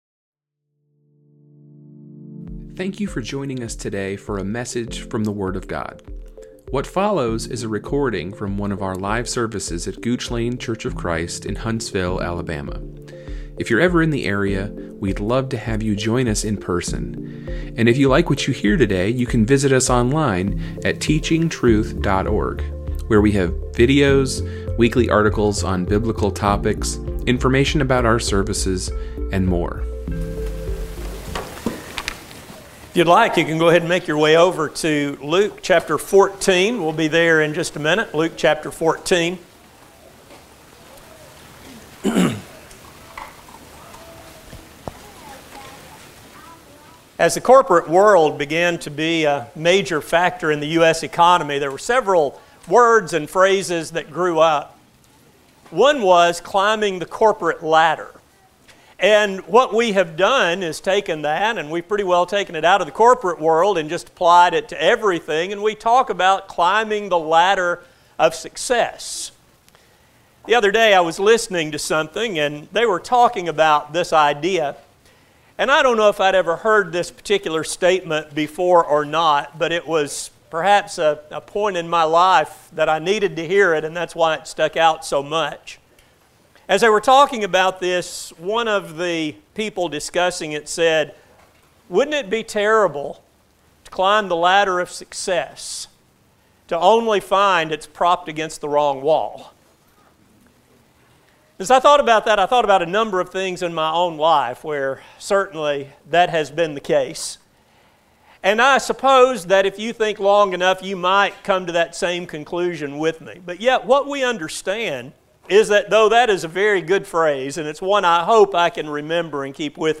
This sermon will explore how easy it is to allow things to derail godly ambitions and how Christians can avoid these pitfalls, while maintaining steely determination to the end. A sermon